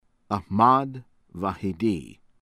VAEEDI, JAVAD jah-VAHD   vah-ee-DEE